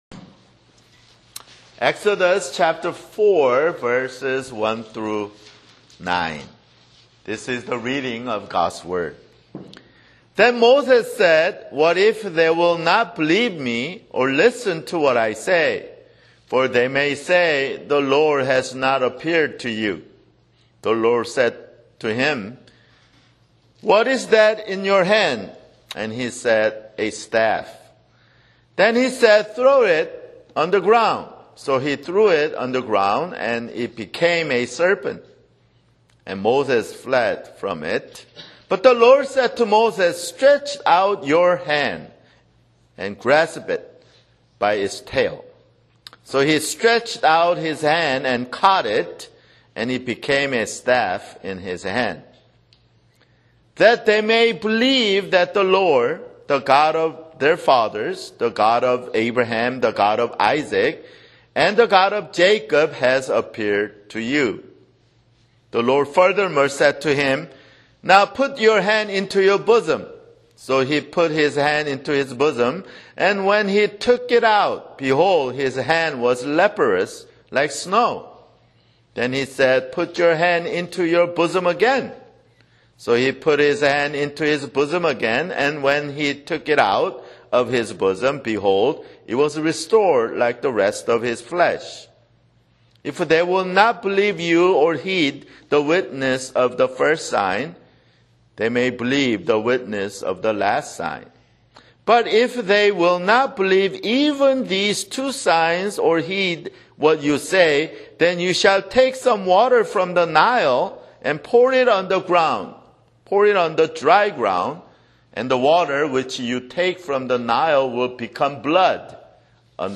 [Sermon] Exodus (8)